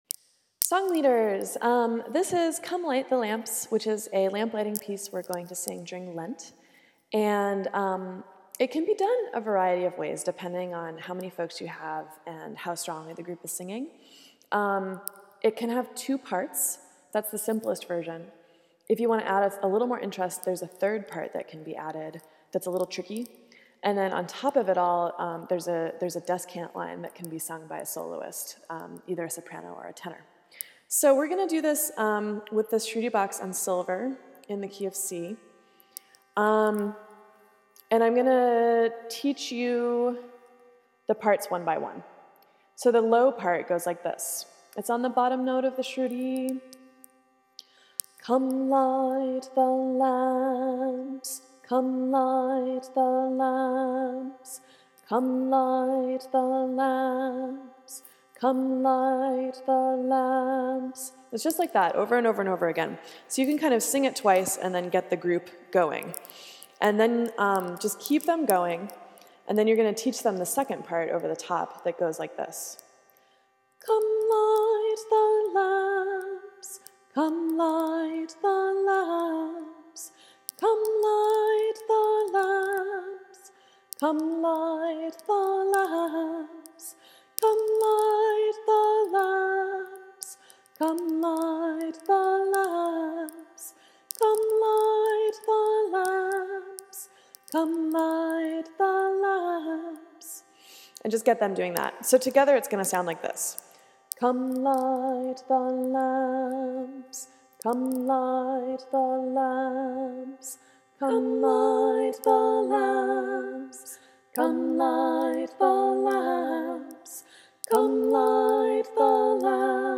Our music for the season reflects that sense of simplicity.
Candlelighting Song
with two parts (or three if we’re really burning) and a solo descant
Come-Light-the-Lamps-3-Part-Descant.m4a